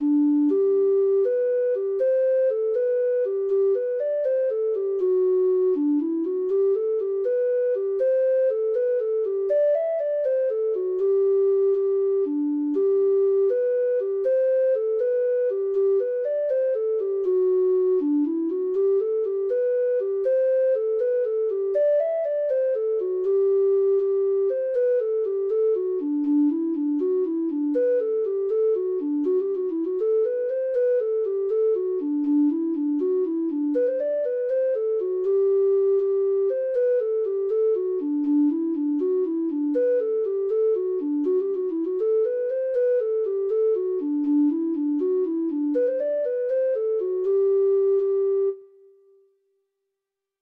Traditional Trad. The Road to Lurgan (Irish Folk Song) (Ireland) Treble Clef Instrument version
Traditional Music of unknown author.
Irish